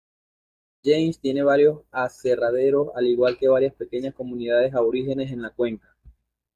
a‧bo‧rí‧ge‧nes
/aboˈɾixenes/